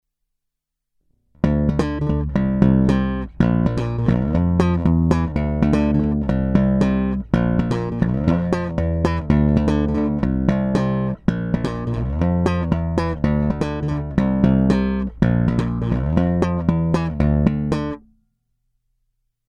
Brutálně konkrétní masívní zvuk.
Není-li uvedeno jinak, následující nahrávky jsou vyvedeny rovnou do zvukové karty a s plně otevřenou tónovou clonou, následně jsou jen normalizovány, jinak ponechány bez úprav.